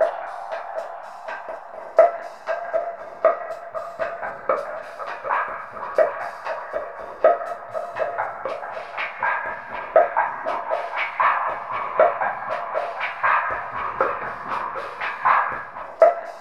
19.2VOXPOP.wav